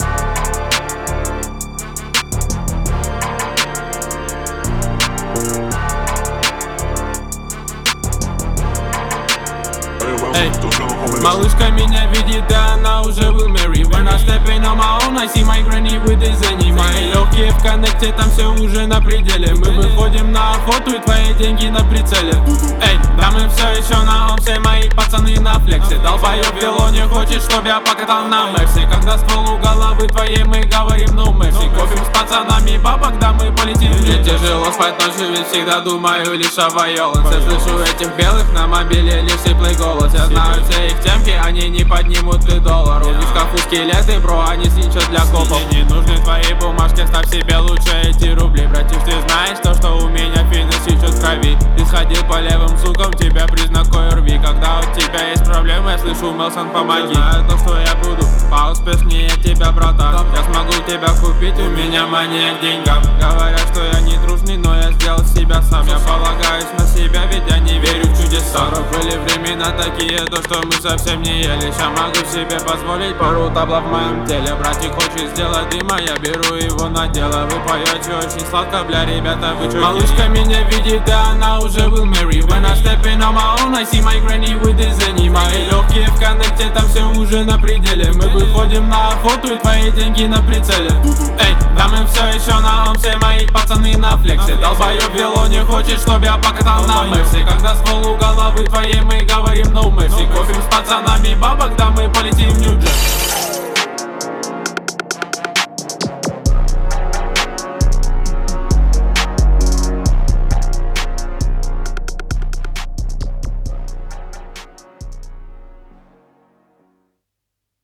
Справедливо, вот трек сведён в наушниках, без использования плагинов для коррекции АЧХ.